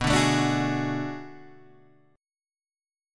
B Minor 13th